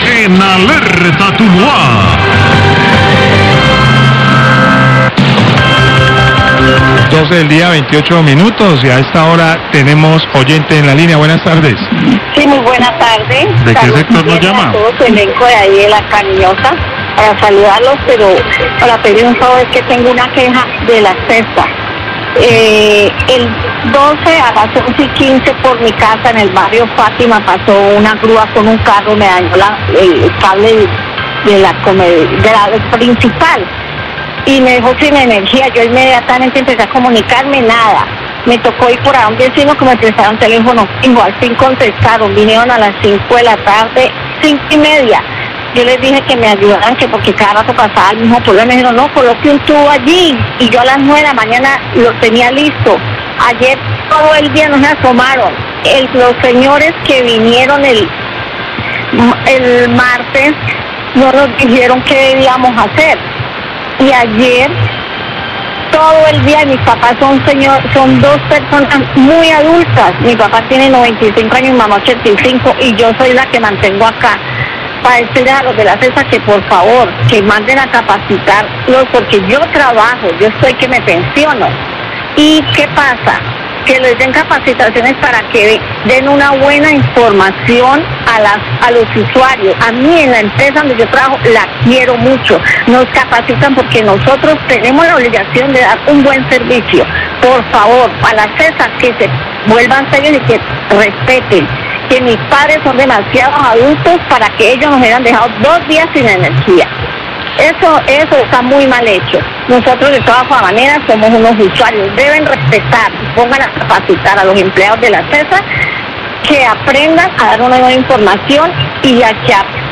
Llamada de oyente se queja porque contratistas de la Epsa no van a su casa a realizar arreglo La Cariñosa 12.27PM